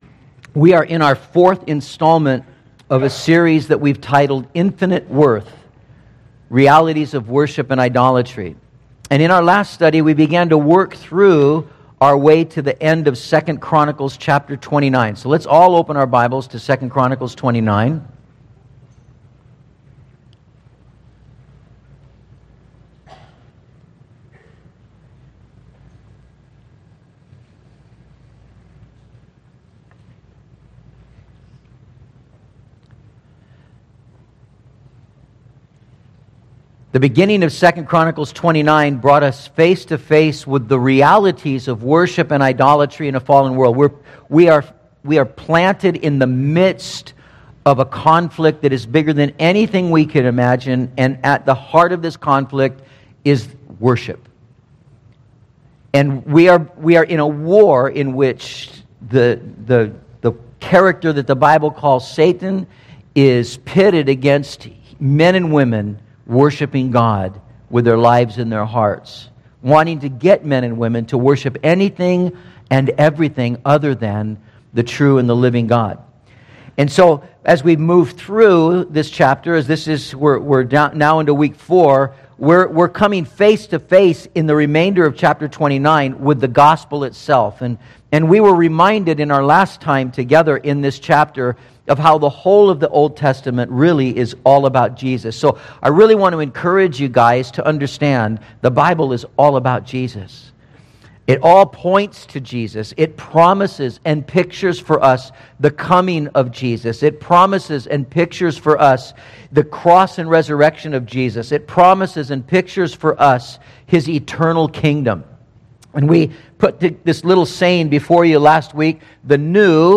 Content from Metro Calvary Sermons